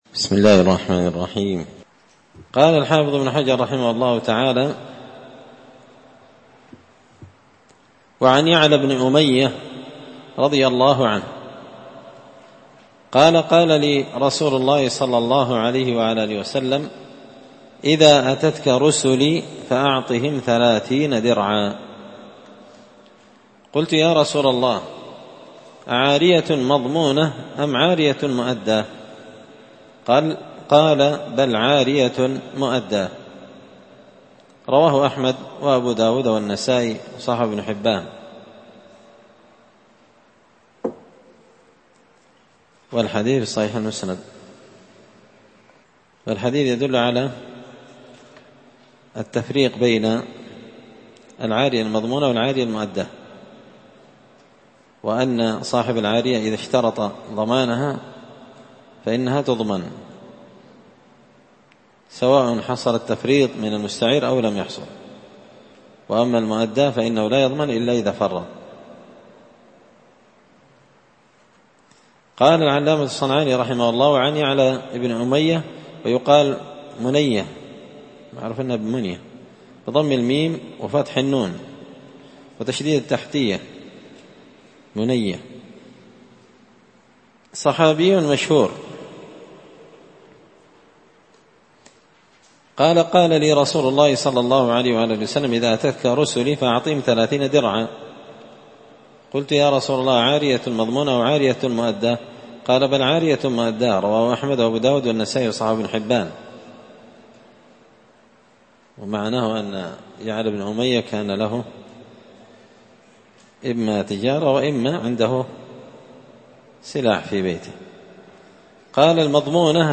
كتاب البيوع من سبل السلام شرح بلوغ المرام لابن الأمير الصنعاني رحمه الله تعالى الدرس -109
مسجد الفرقان_قشن_المهرة_اليمن